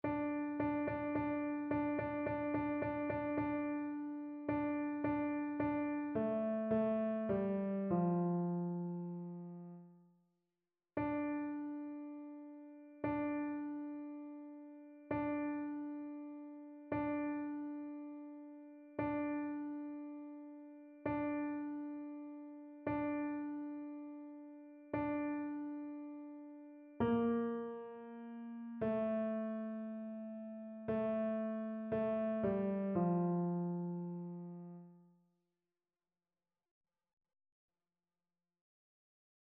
TénorBasse